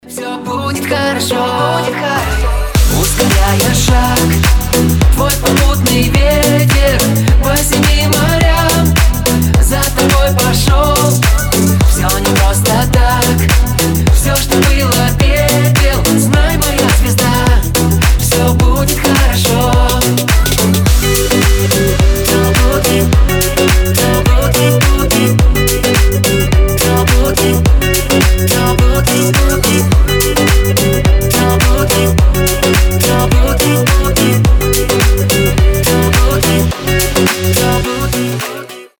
• Качество: 320, Stereo
поп
позитивные
громкие
зажигательные